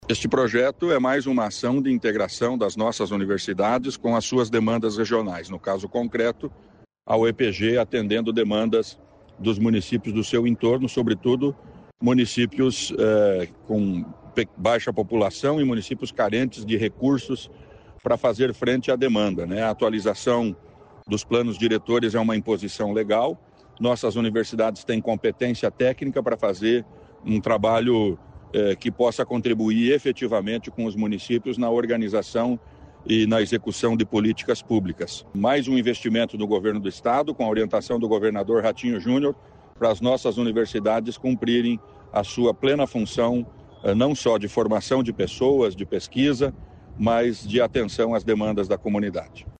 Sonora do secretário da Ciência, Tecnologia e Ensino Superior, Aldo Nelson Bona, sobre projeto de apoio à revisão do plano diretor para municípios de pequeno porte